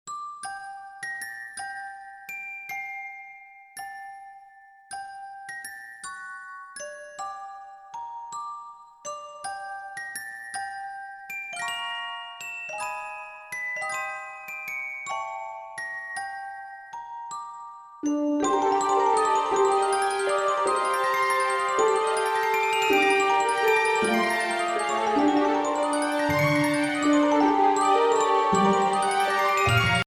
Voicing: Bb Clarinet w/ Audio